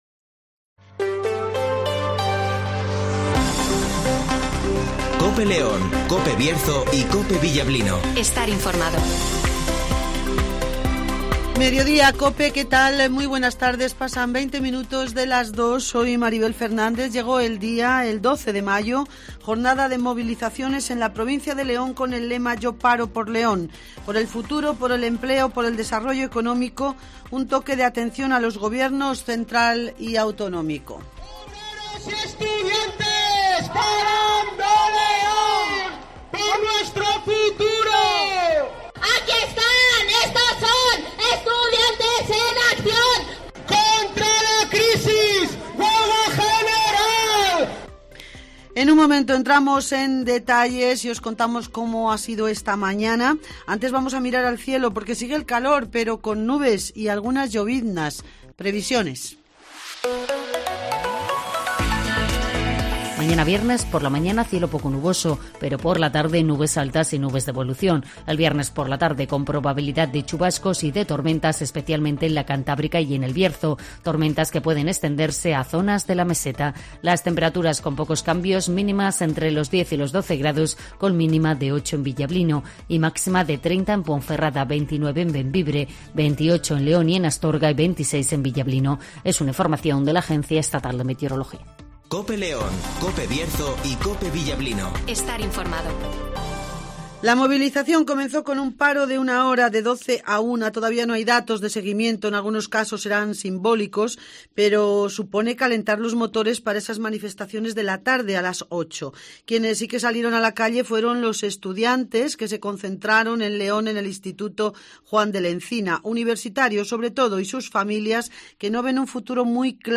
Gritos Manifestación " 12 Mayo "